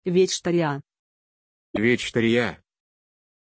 Pronunciation of Victoriah
pronunciation-victoriah-ru.mp3